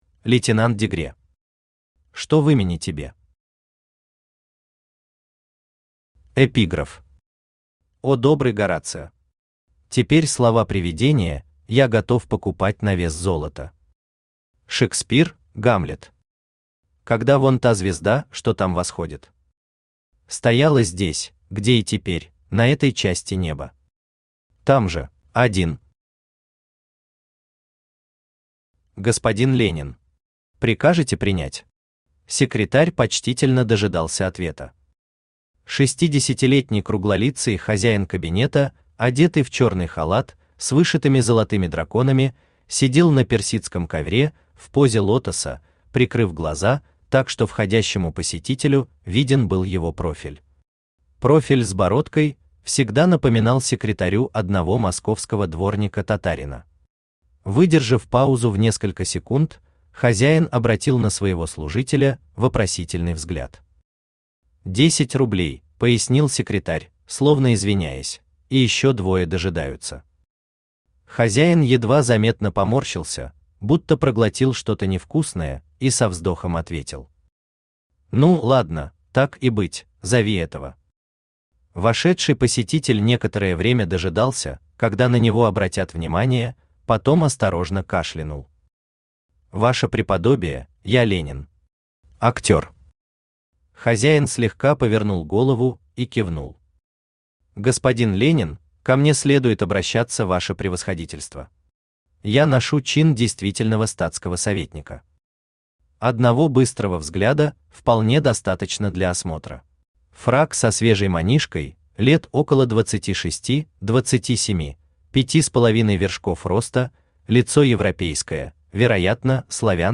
Аудиокнига Что в имени тебе?
Автор Лейтенант Дегре Читает аудиокнигу Авточтец ЛитРес.